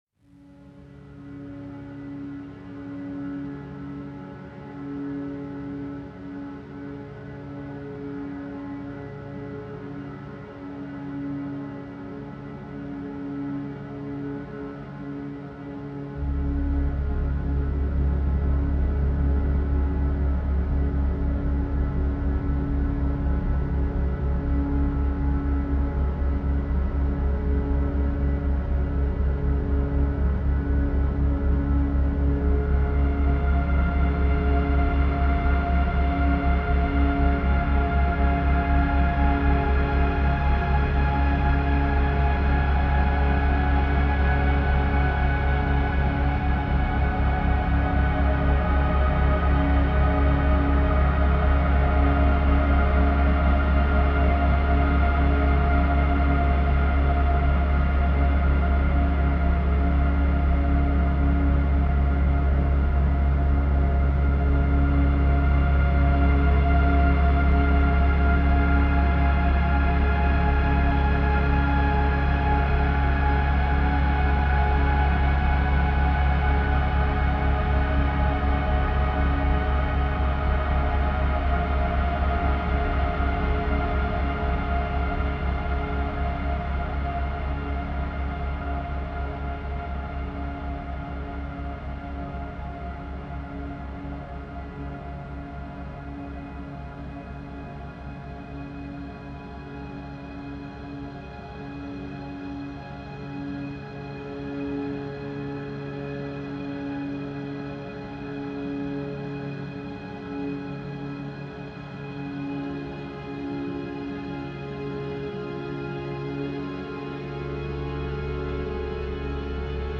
Dark Drone Version